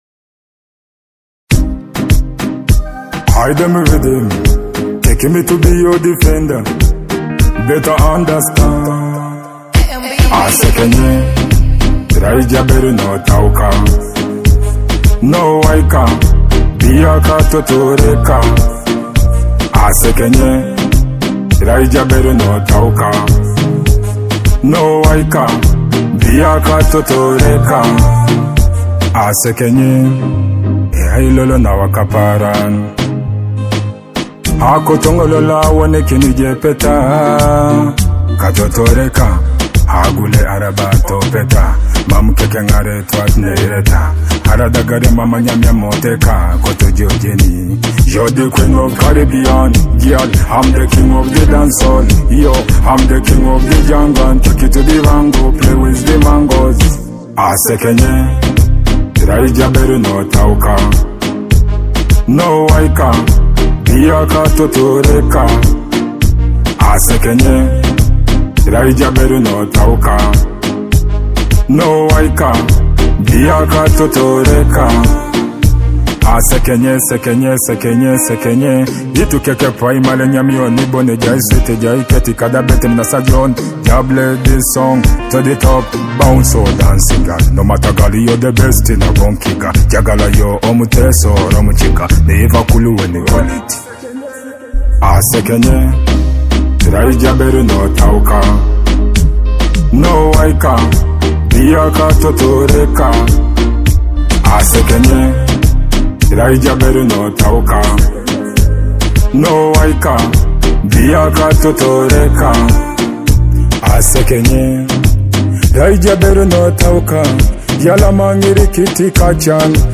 a captivating blend of rhythmic beats and powerful vocals.